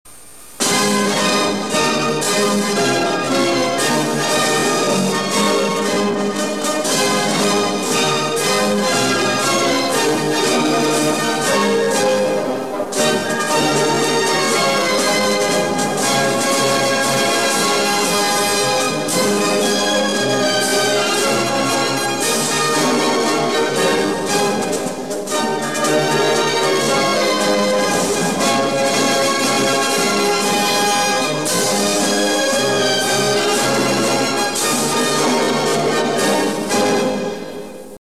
19:03, 17 апреля 2025 Mazurka Gullermanův (official instrumental).mp3 (файл)